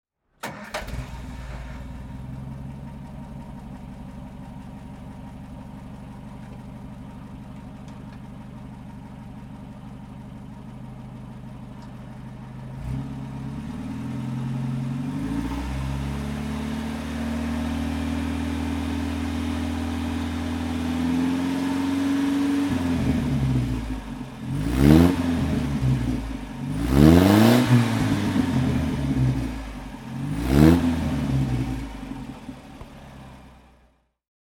Engine sounds of Morris vehicles (random selection)